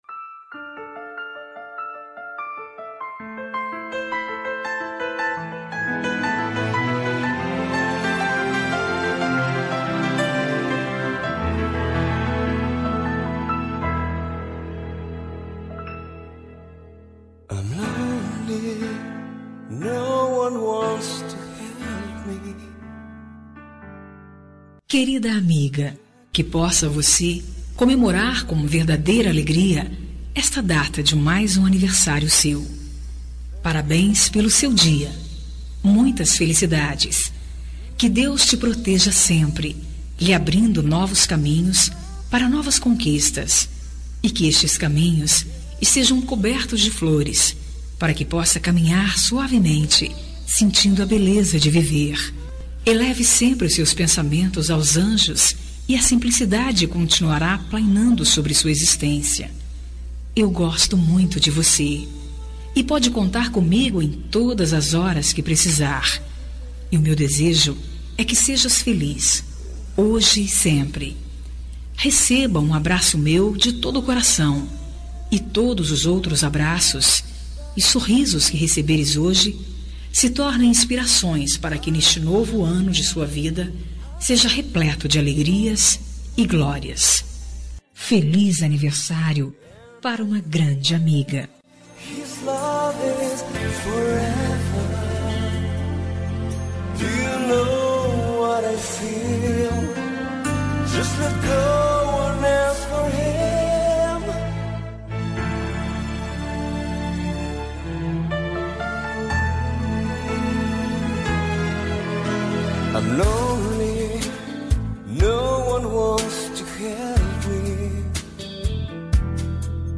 Aniversário de Amiga Gospel – Voz Feminina – Cód: 6020